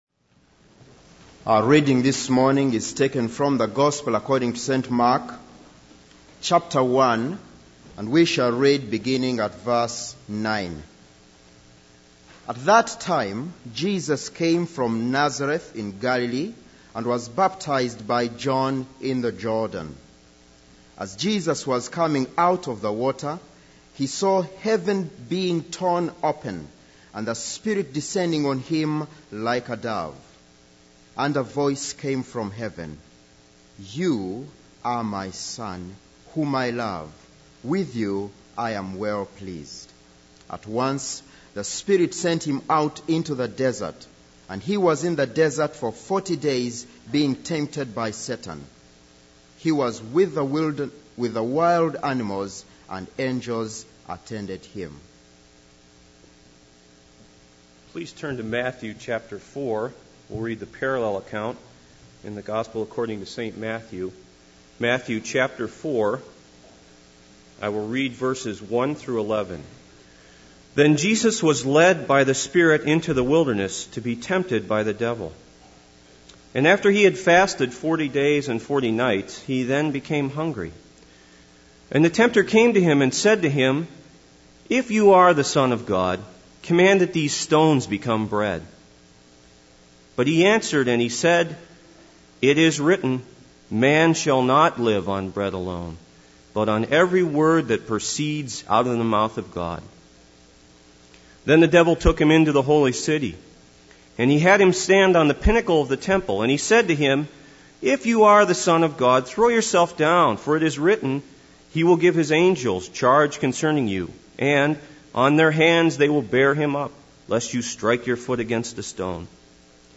This is a sermon on Mark 1:12-13 & Matthew 4:1-11.